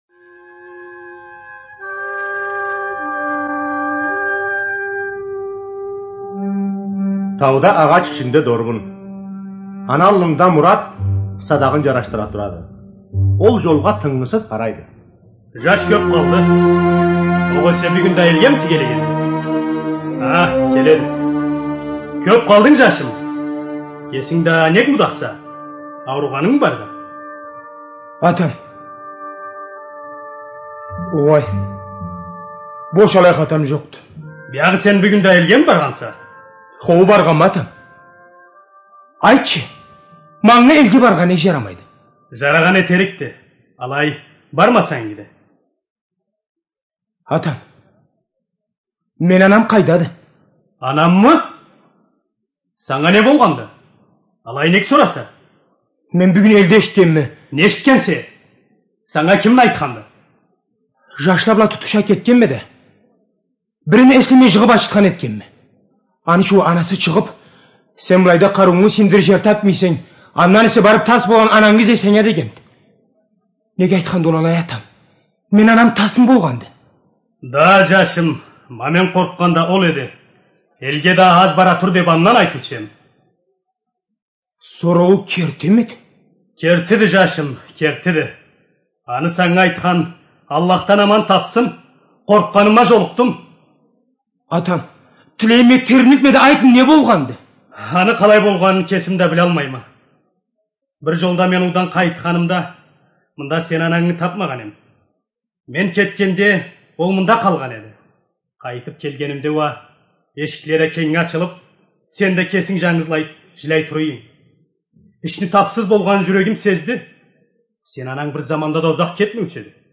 ЖанрРадиоспектакль на языках народов России